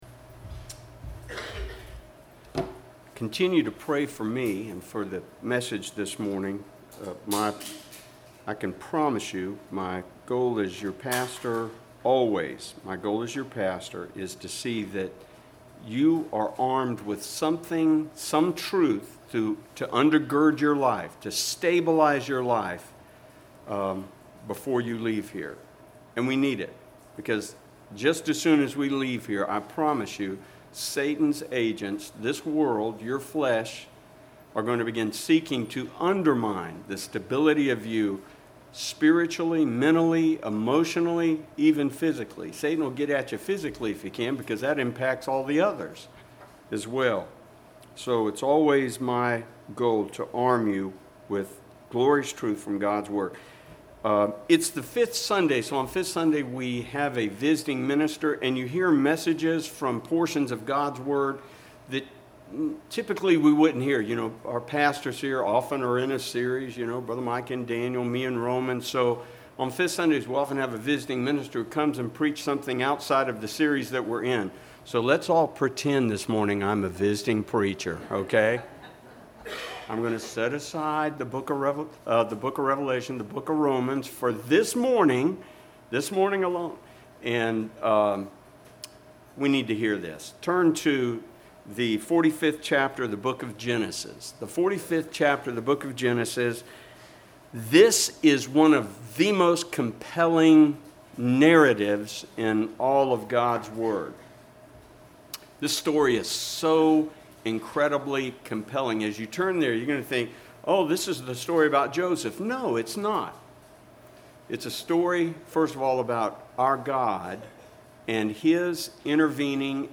Sermons - Macclenny Primitive Baptist Church - Page 8